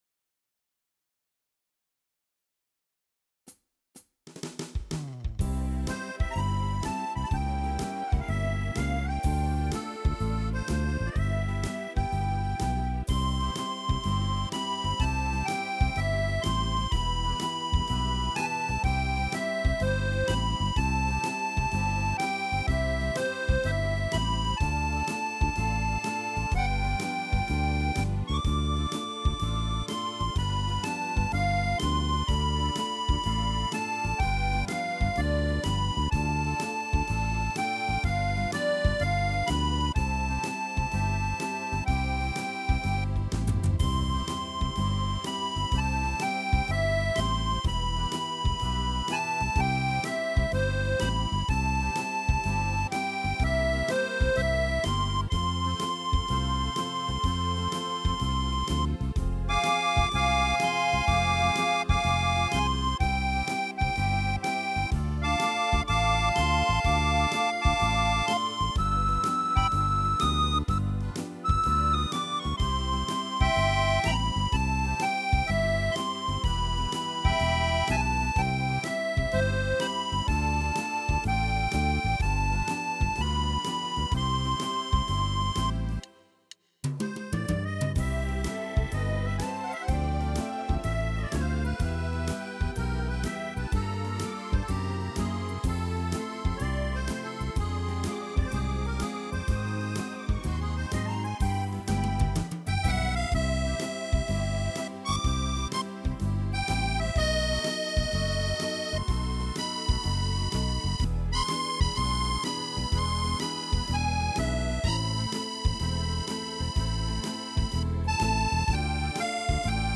As músicas foram executadas com os seguintes teclados:
MÚSICAS EXECUTADAS COM O TECLADO YAMAHA PSR-SX700